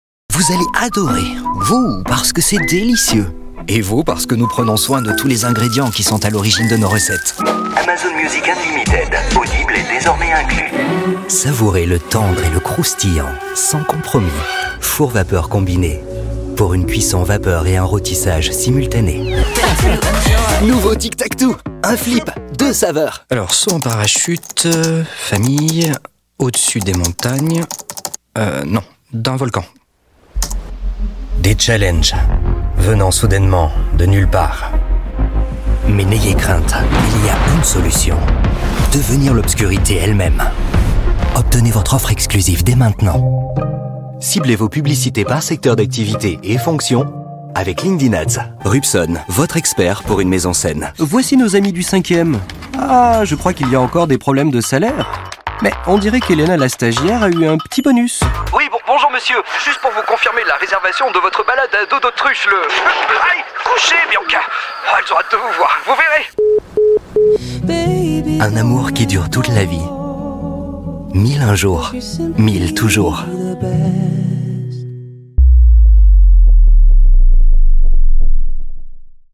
French voice over
Natural, Accessible, Friendly
Commercial